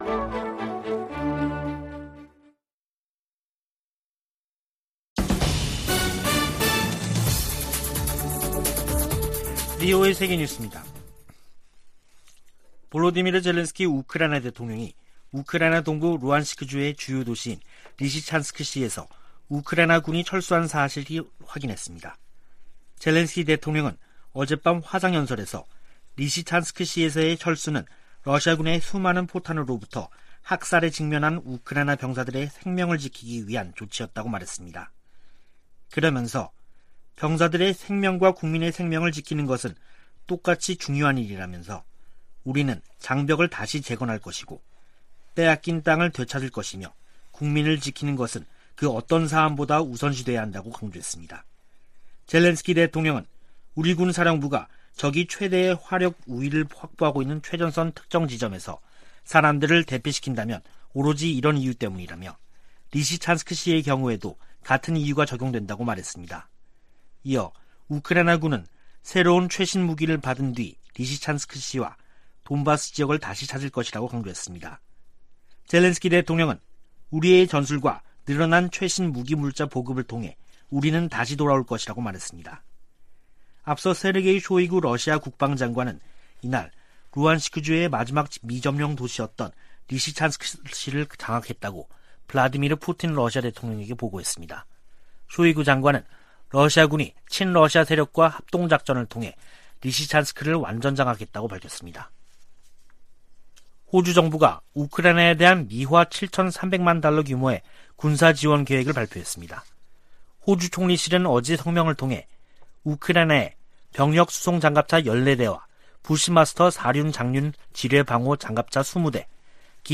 VOA 한국어 간판 뉴스 프로그램 '뉴스 투데이', 2022년 7월 4일 3부 방송입니다. 미국은 북한의 핵·미사일 도발에 대응하고 한반도 비핵화를 위해 동맹과 협력할 것이라고 미군 당국이 밝혔습니다. 위협을 가하는 북한에 일방적으로 대화와 협력을 요청해서는 변화시킬 수 없다고 전 국무부 동아시아태평양 담당 차관보가 진단했습니다. 미국 전문가들은 중국에 대한 한국의 전략적 모호성은 이익보다 대가가 클 것이라고 말했습니다.